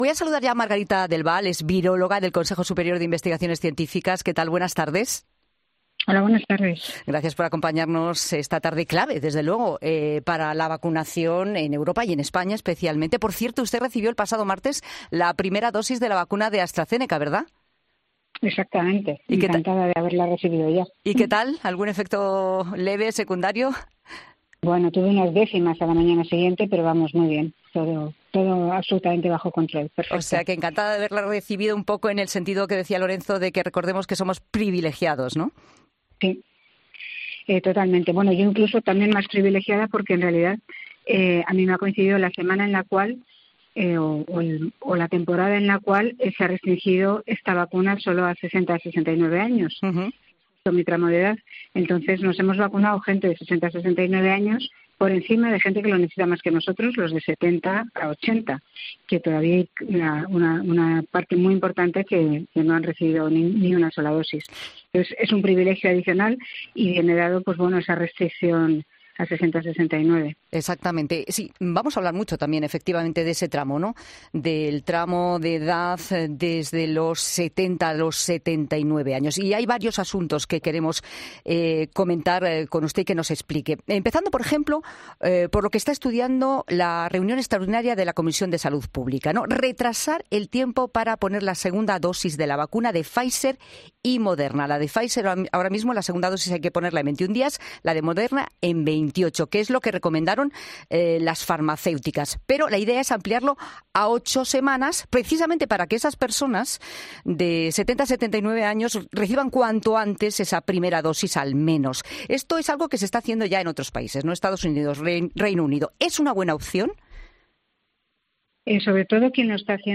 En 'La Tarde' hablamos con Margarita del Val, en una semana clave para determinar si el ritmo de vacunación es bueno o no
La decisión de la EMA sobre la vacuna de Janssen que Margarita del Val ha conocido en directo en COPE